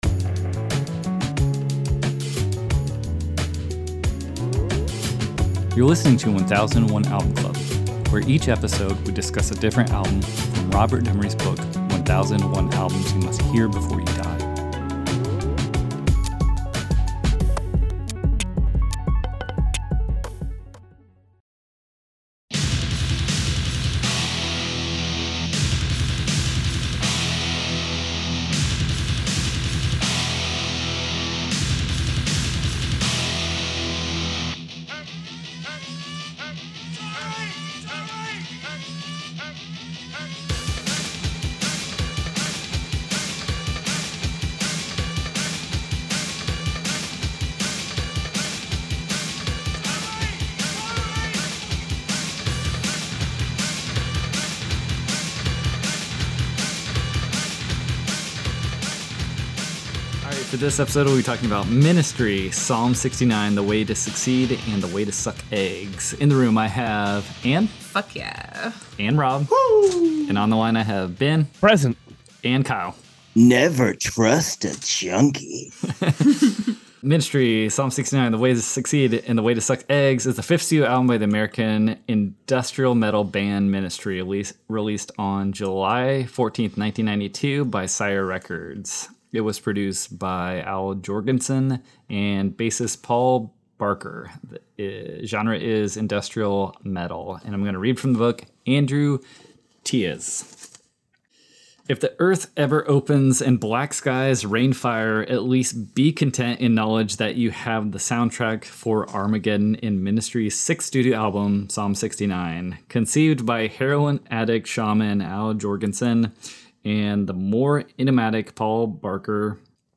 Friends make a pact to listen and discuss 1001 of the best albums in a basement tiki bar